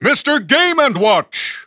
The announcer saying Mr. Game & Watch's name in English and Japanese releases of Super Smash Bros. Brawl.
Mr._Game_&_Watch_English_Announcer_SSBB.wav